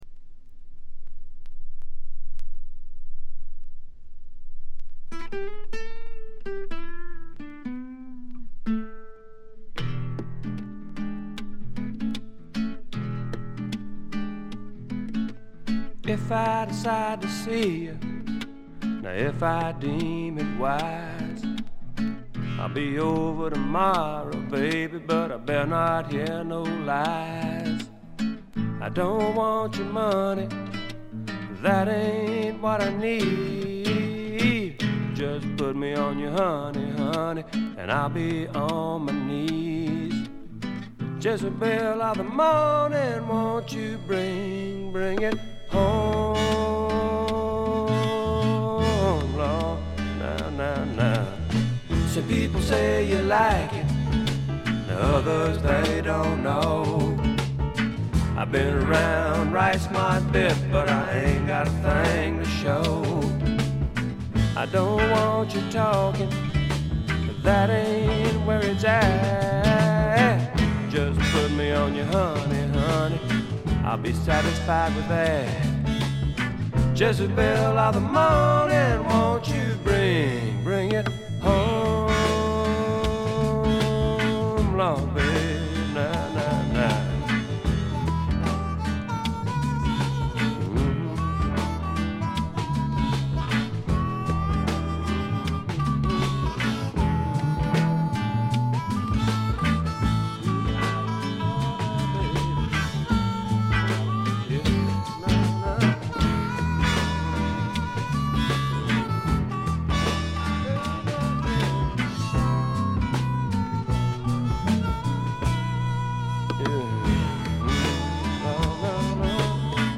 部分試聴ですが、軽微なチリプチ少々。
あまりナッシュビルぽくないというかカントリーぽさがないのが特徴でしょうか。
試聴曲は現品からの取り込み音源です。
Guitar, Vocals, Piano, Vibes